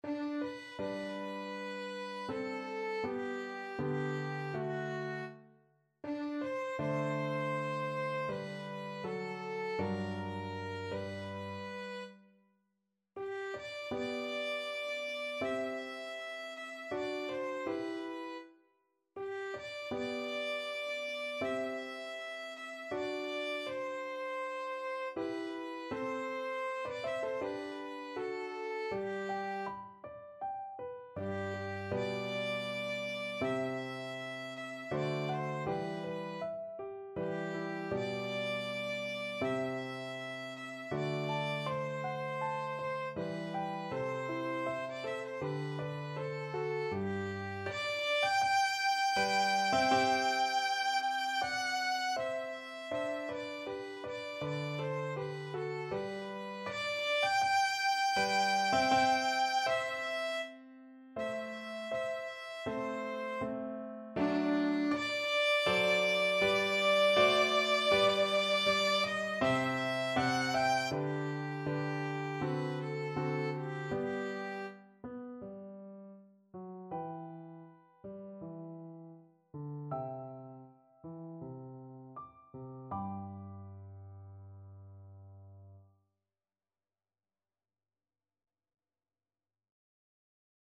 Violin version
4/4 (View more 4/4 Music)
Andante
Classical (View more Classical Violin Music)